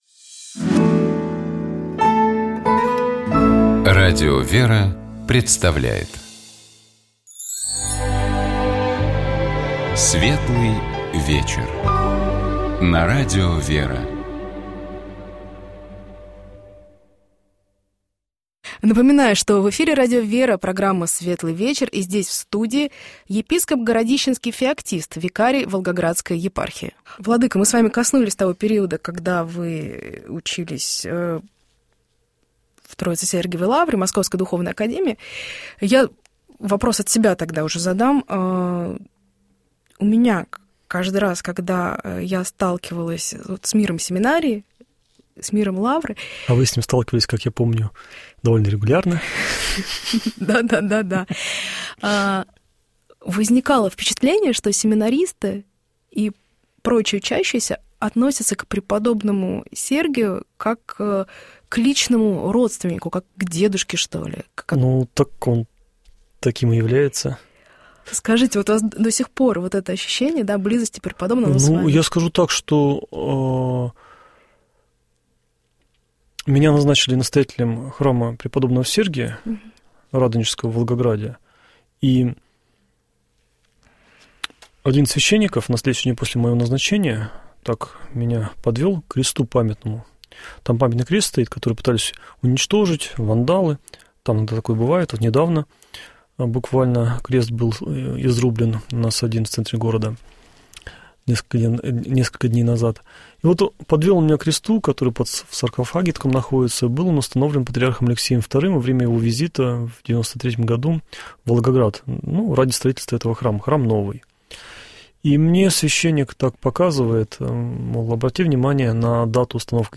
У нас в гостях был епископ Городищенский, викарий Волгоградской епархии Феоктист.